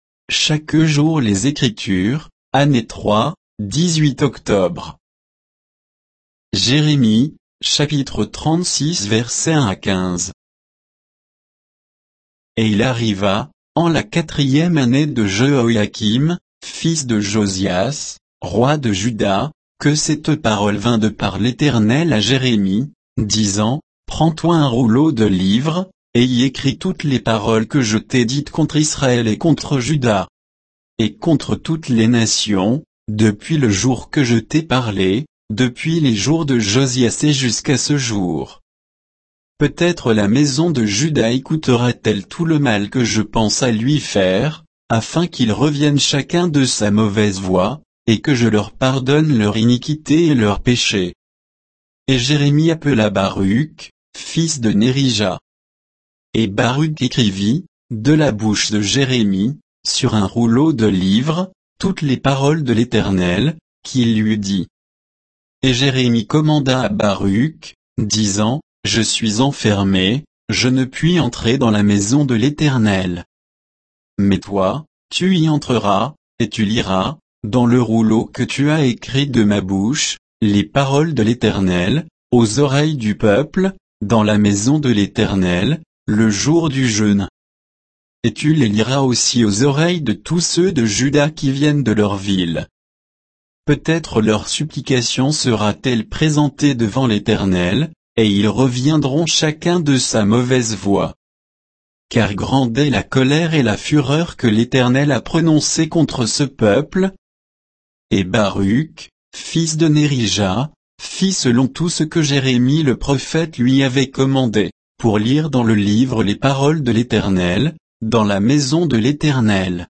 Méditation quoditienne de Chaque jour les Écritures sur Jérémie 36